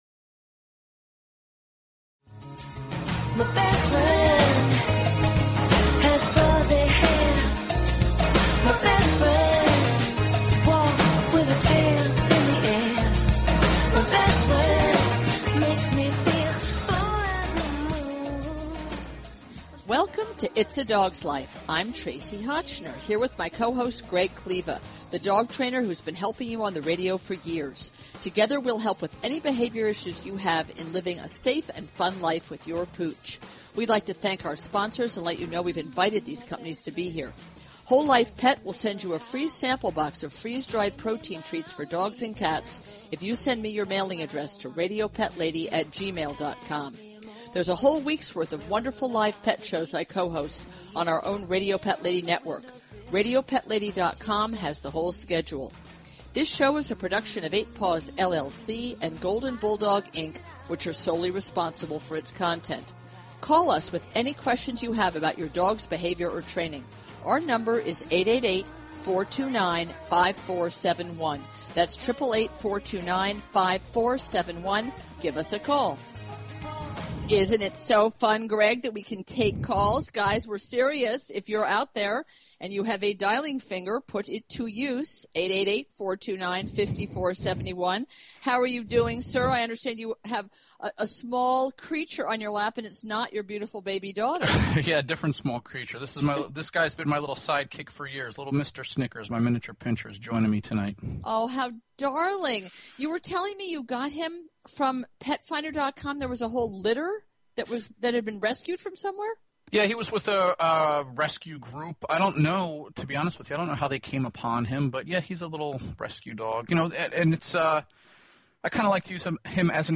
Talk Show Episode, Audio Podcast, Its_A_Dogs_Life and Courtesy of BBS Radio on , show guests , about , categorized as